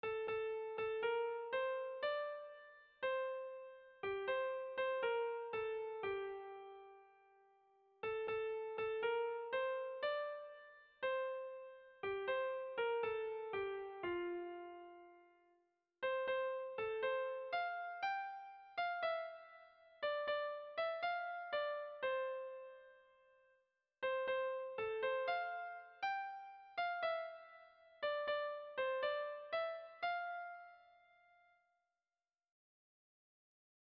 Kontakizunezkoa
Zortziko txikia (hg) / Lau puntuko txikia (ip)
A-A-B1-B2